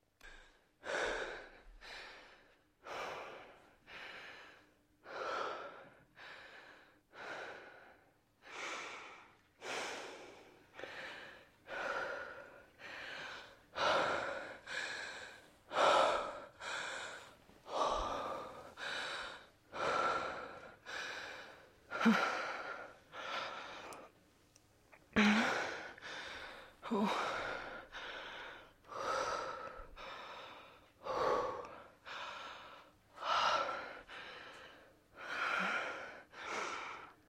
Звуки вздоха человека
Звук дыхания для успокоения после стресса